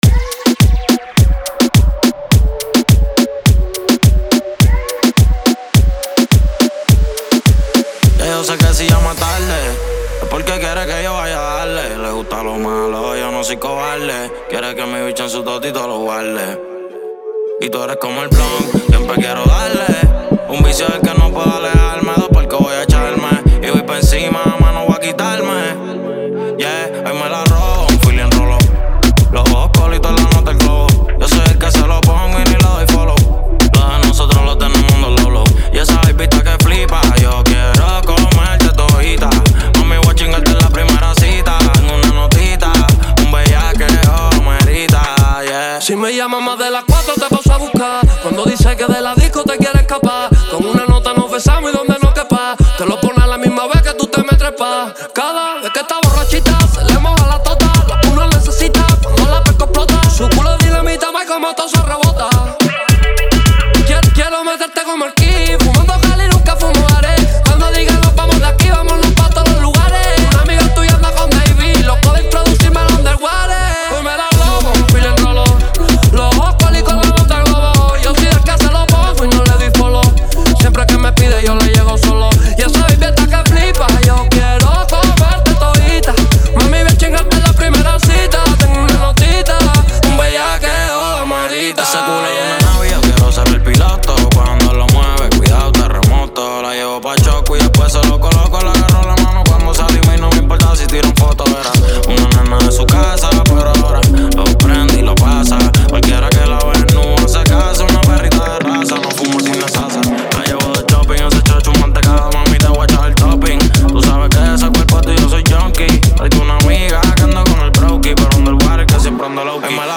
BPM: 105